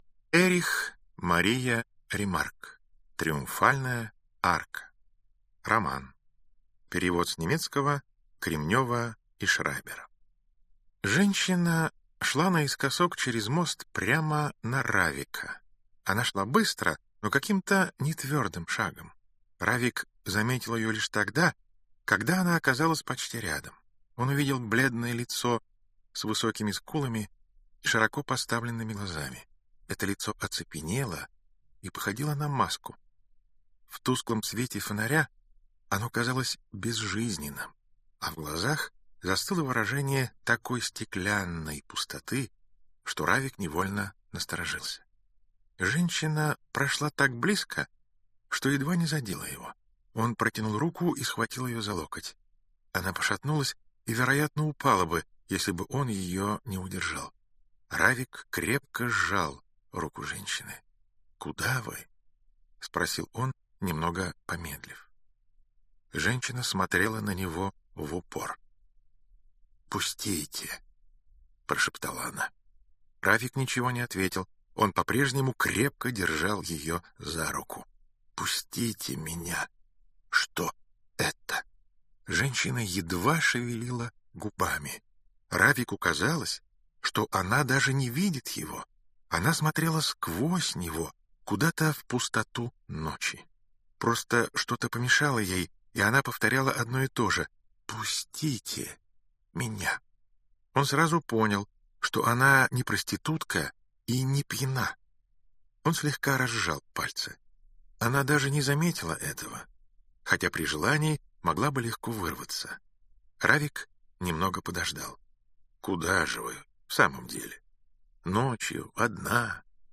Аудиокнига Триумфальная арка | Библиотека аудиокниг